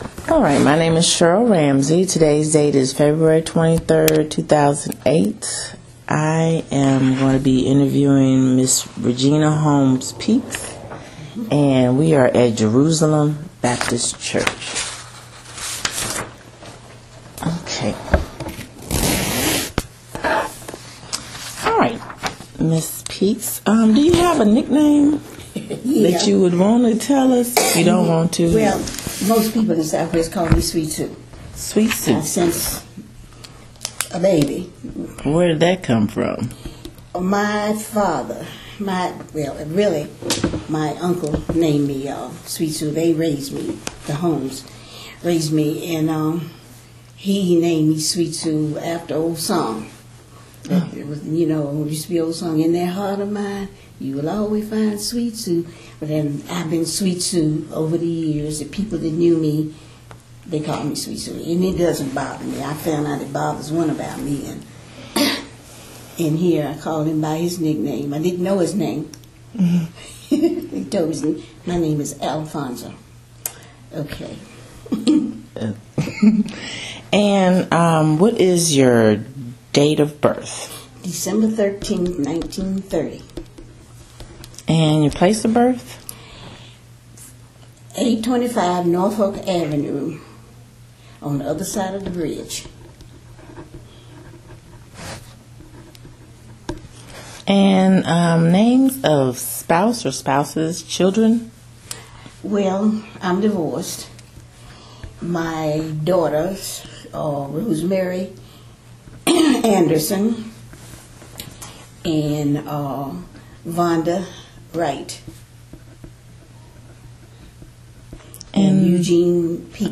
Neighborhood History Interview
Location: Jerusalem Baptist Church